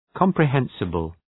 Προφορά
{,kɒmprı’hensəbəl}